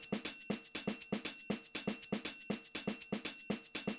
Drumloop 120bpm 04-B.wav